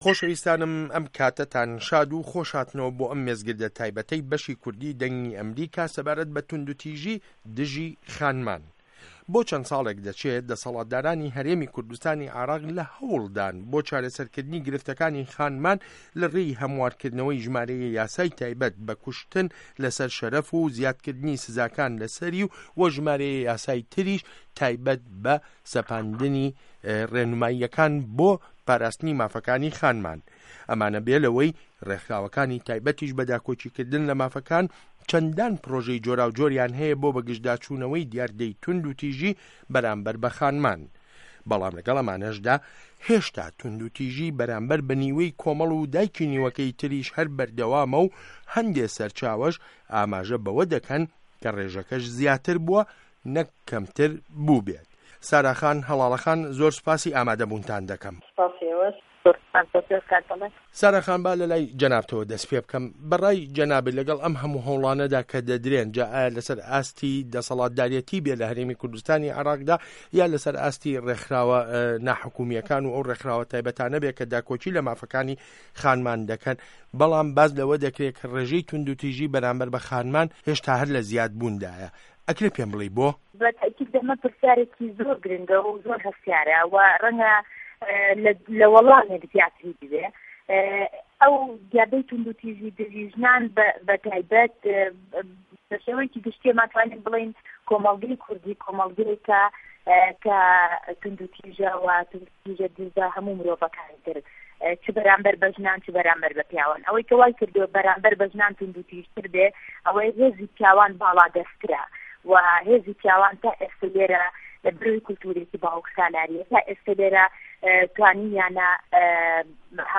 مێزگرد له‌سه‌ر توندوتیژی به‌رامبه‌ر خانمان